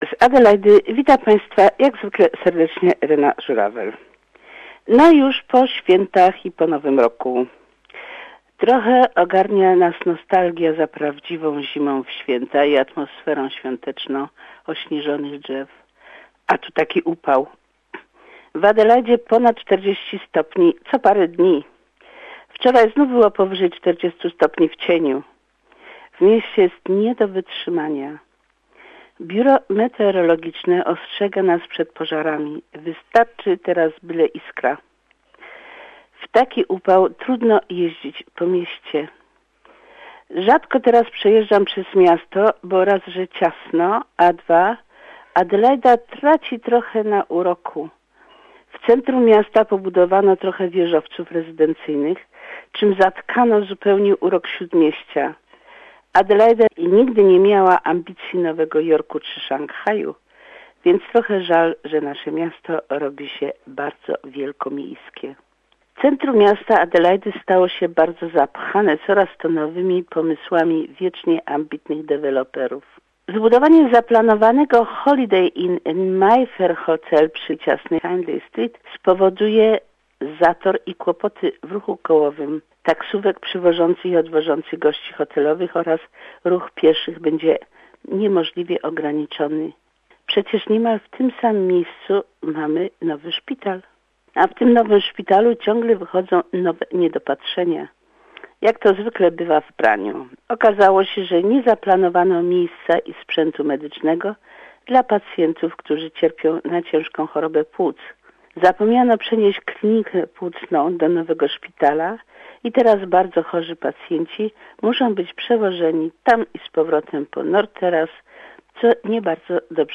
Report from South Australia